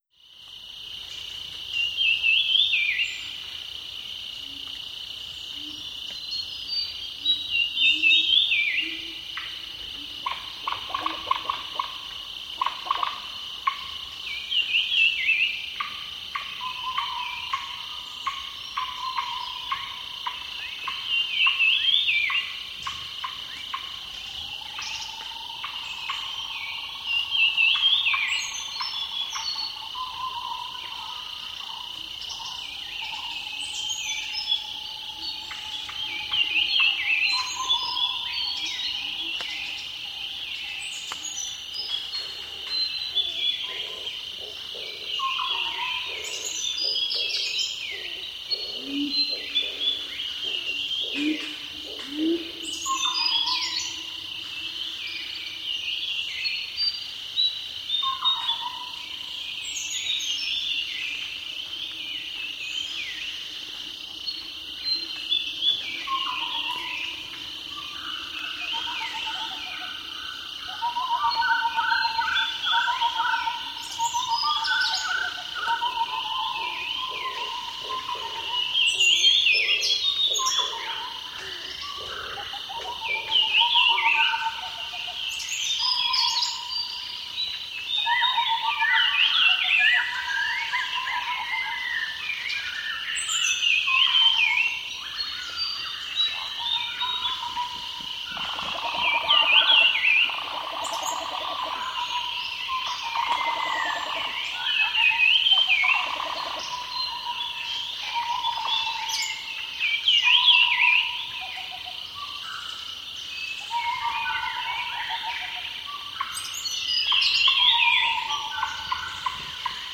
• birdsongs in lowland forest.wav
birdsongs_in_lowland_forest_ZqZ.wav